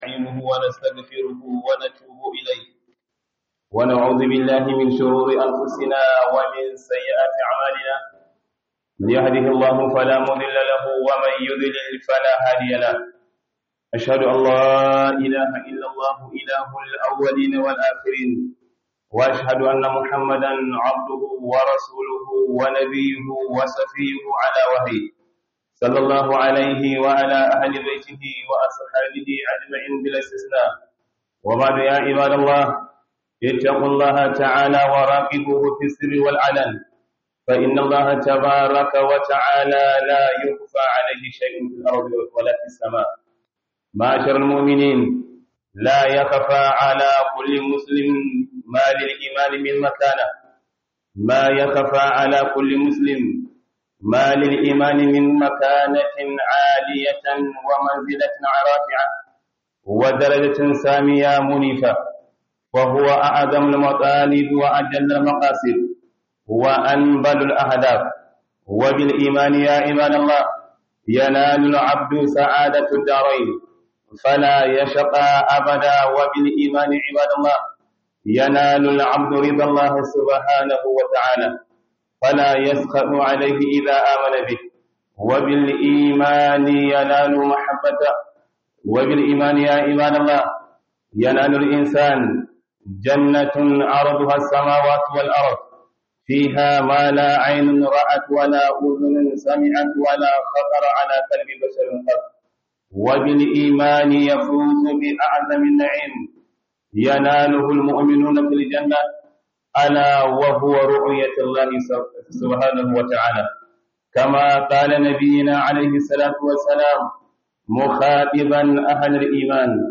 Book KHUDUBAR JUMA'A